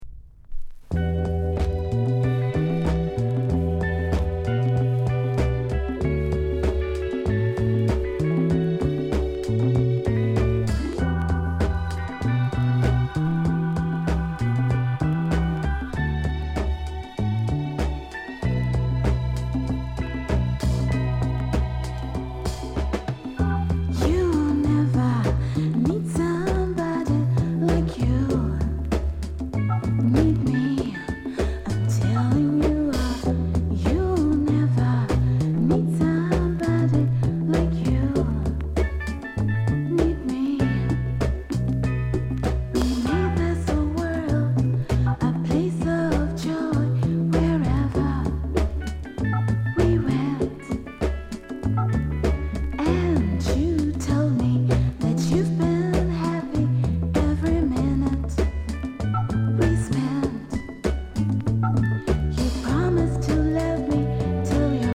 SOULFUL REGGAE